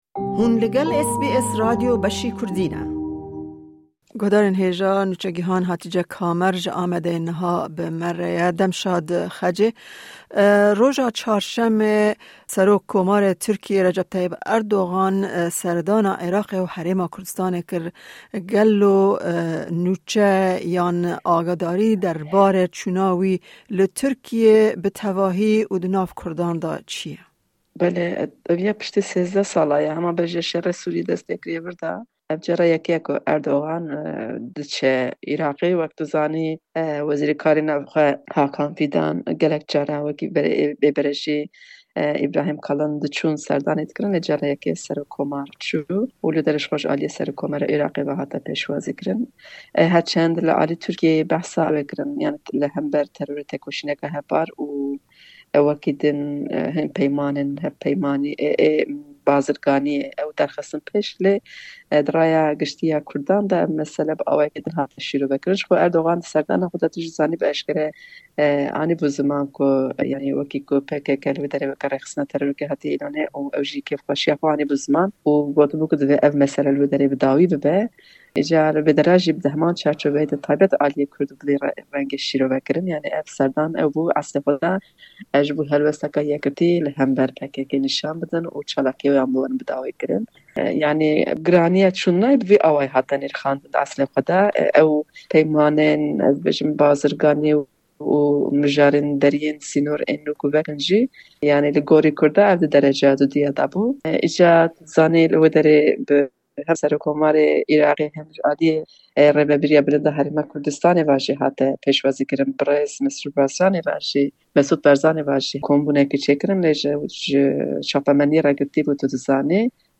Peyamnêr